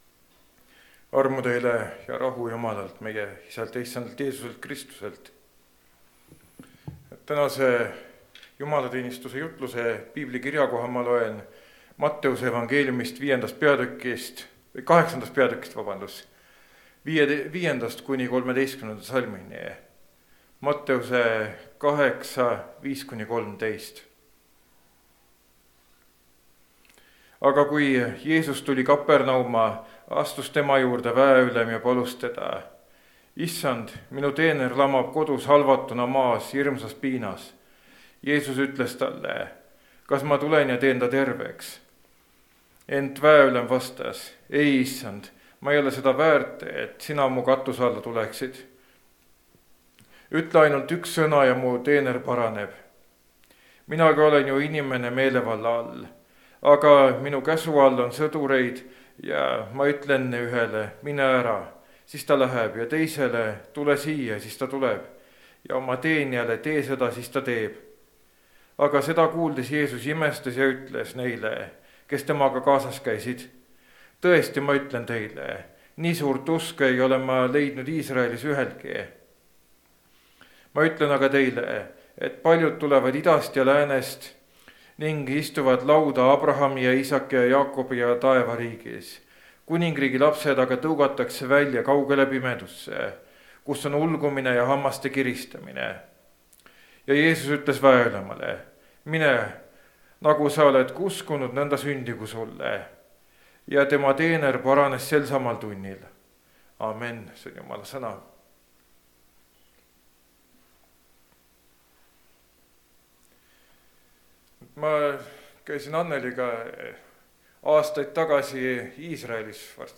Väepealiku usk (Rakveres)
Jutlused